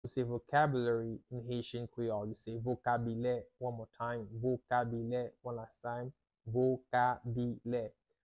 How to say "Vocabulary " in Haitian Creole - "Vokabilè " pronunciation by a native Haitian Tutor
“Vokabilè” Pronunciation in Haitian Creole by a native Haitian can be heard in the audio here or in the video below:
How-to-say-Vocabulary-in-Haitian-Creole-Vokabile-pronunciation-by-a-native-Haitian-tutor.mp3